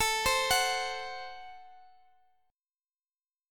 Listen to Am6 strummed